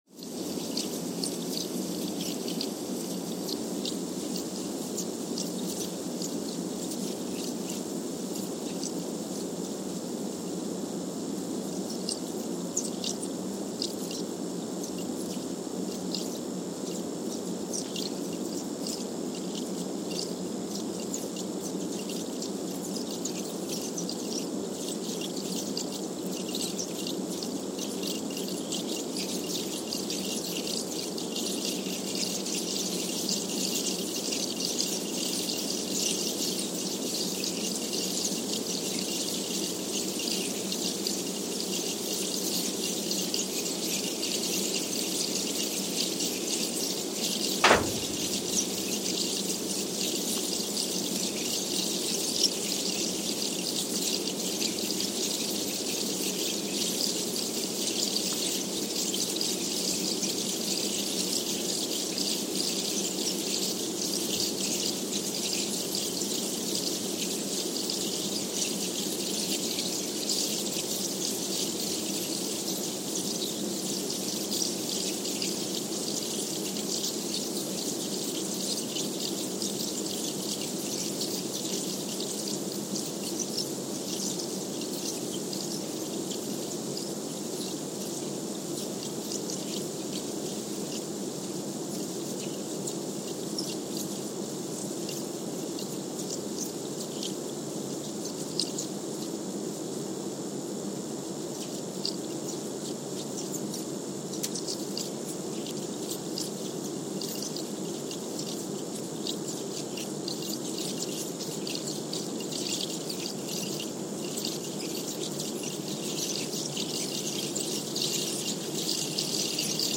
San Juan, Puerto Rico (seismic) archived on February 5, 2023
Station : SJG (network: IRIS/USGS) at San Juan, Puerto Rico
Sensor : Trillium 360
Speedup : ×1,000 (transposed up about 10 octaves)
Loop duration (audio) : 05:45 (stereo)
Gain correction : 25dB
SoX post-processing : highpass -2 90 highpass -2 90